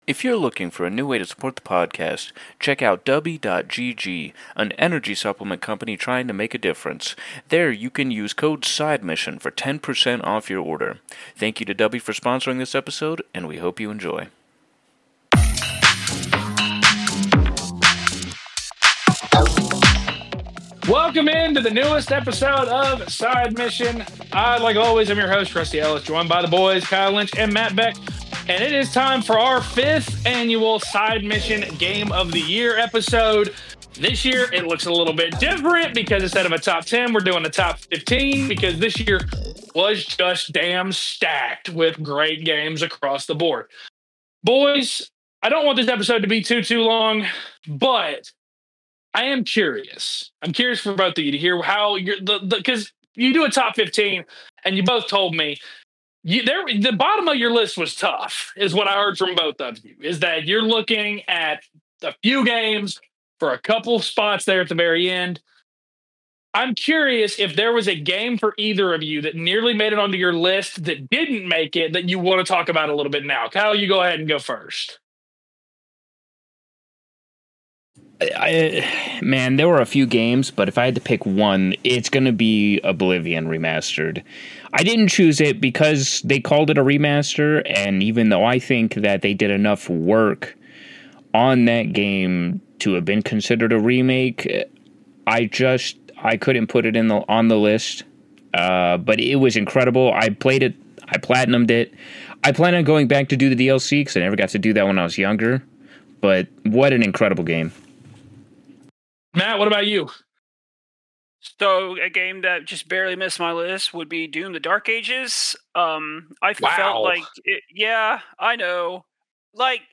Four friends, who love experiencing old-school classics and new, original content, give their takes on the latest news and topics surrounding the gaming industry.